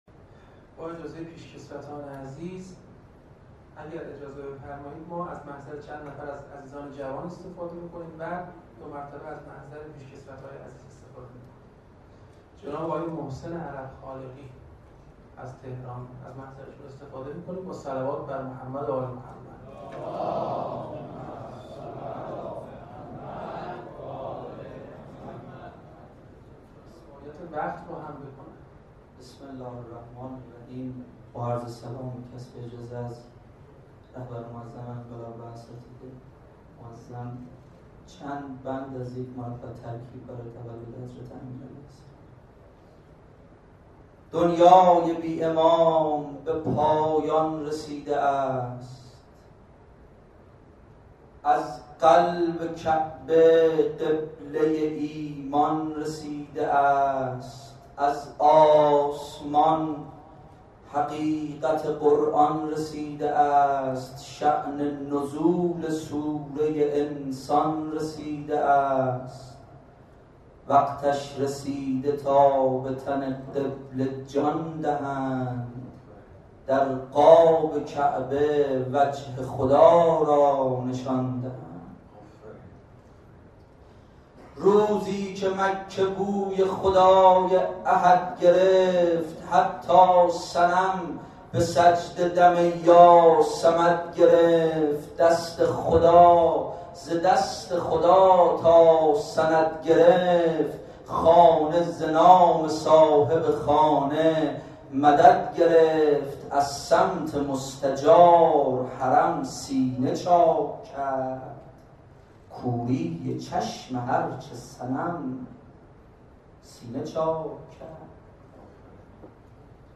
به گزارش خبرگزاری تسنیم، دو سال پیش در آستانه ولادت باسعادت امیرالمؤمنین، علی‌بن ابی‌طالب علیه السلام جمعی از شاعران آئینی، سروده‌های خود را با مضامین عمدتاً مذهبی، اخلاقی و نیز بیداری اسلامی در محضر رهبر انقلاب اسلامی قرائت کردند.